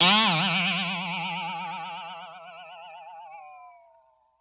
Boing (1).wav